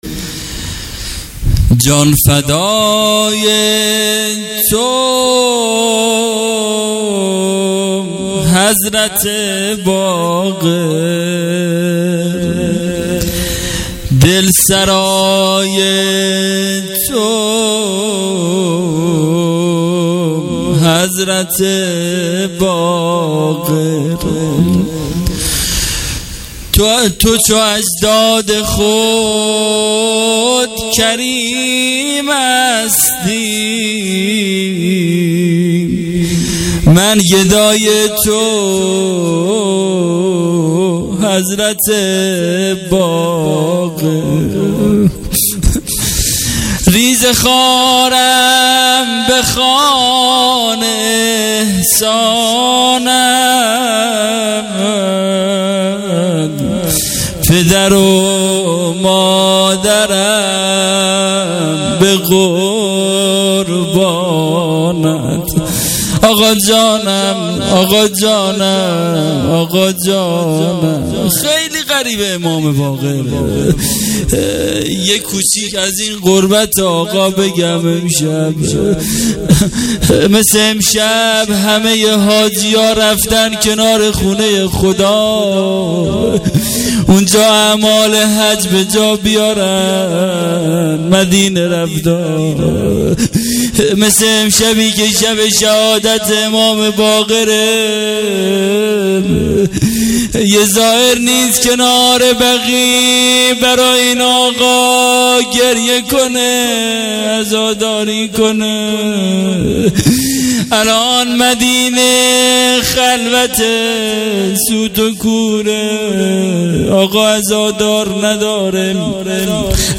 هیئت عاشورا-قم
مداحی